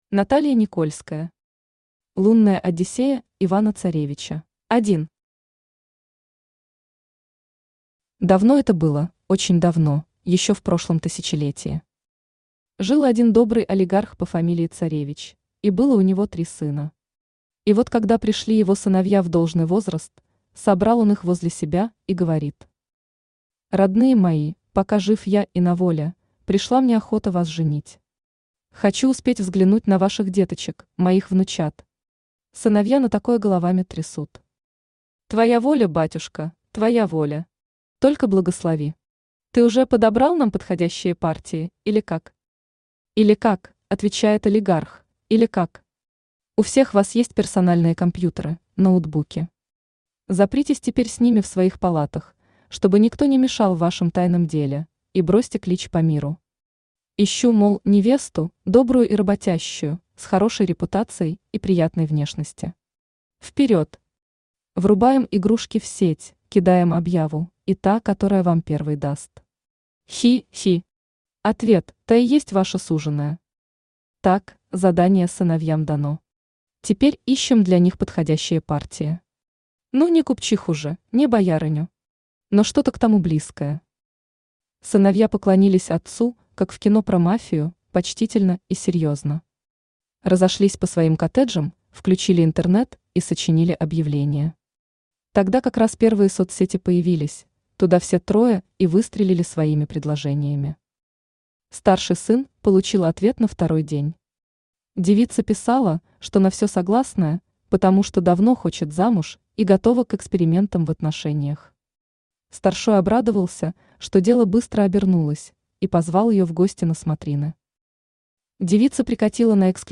Аудиокнига Лунная одиссея Ивана Царевича | Библиотека аудиокниг
Aудиокнига Лунная одиссея Ивана Царевича Автор Наталья Никольская Читает аудиокнигу Авточтец ЛитРес.